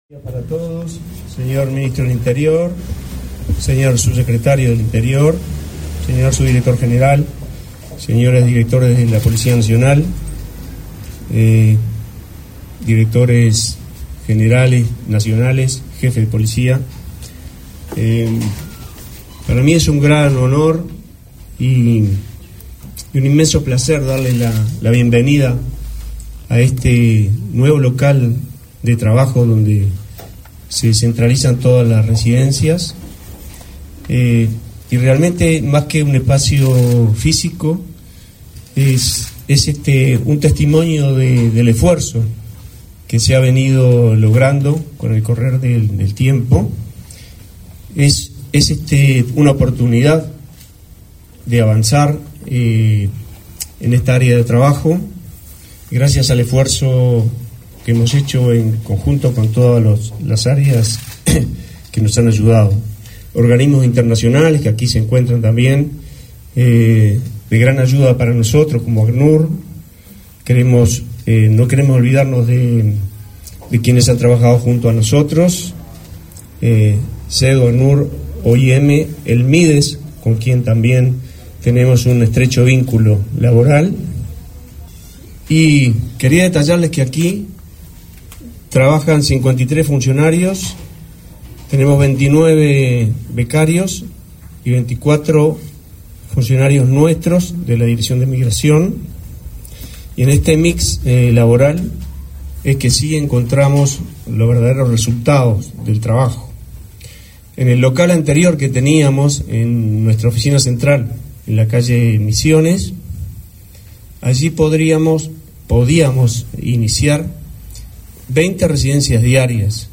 Palabras del director nacional de Migraciones, Eduardo Mata 30/05/2024 Compartir Facebook X Copiar enlace WhatsApp LinkedIn El ministro de Interior, Nicolás Martinelli, junto al subsecretario, Pablo Abdala, participaron, este 30 de mayo, en la inauguración del Departamento de Residencias de la Dirección Nacional de Migración. En el evento disertó el director nacional de Migraciones, Eduardo Mata.